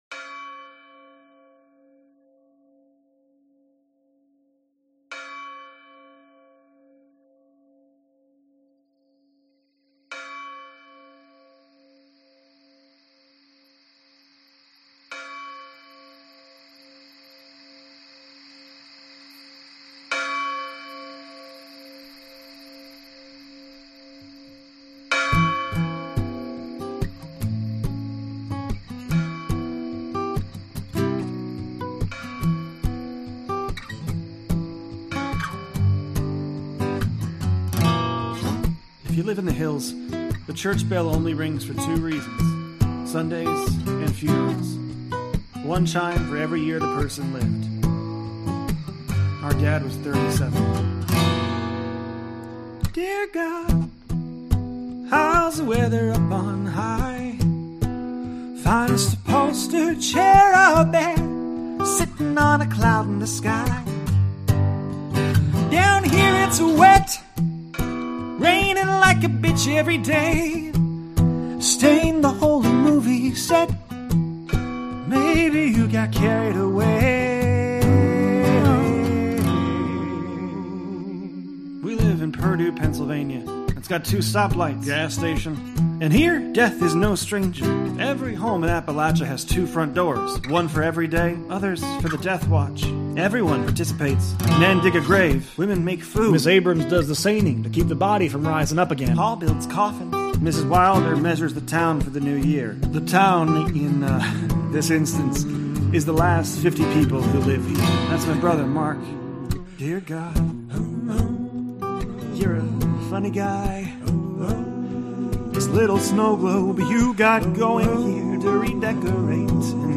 HILLS ON FIRE - Scenes 1-3 Scratch recordings